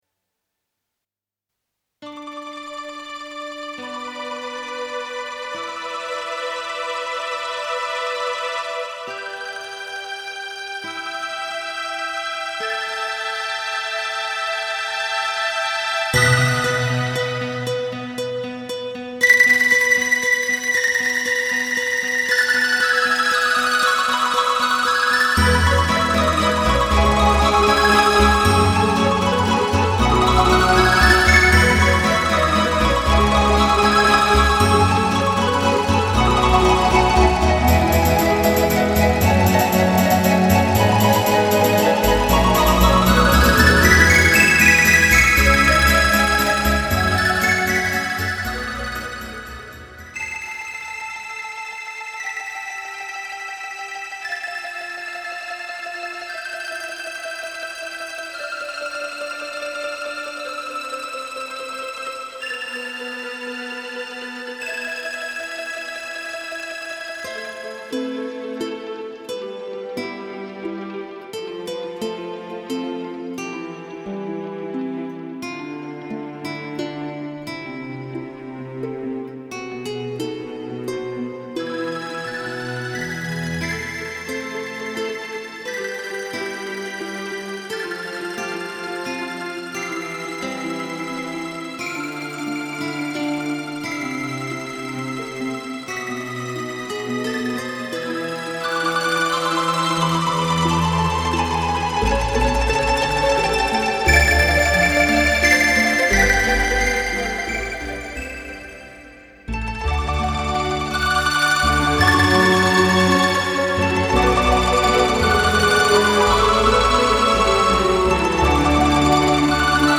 Mandolin Reference CD - No.12